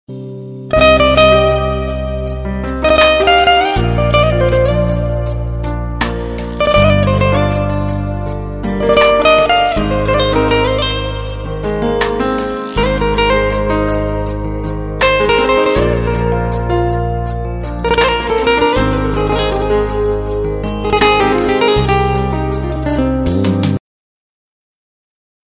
رینگتون ملایم و رمانتیک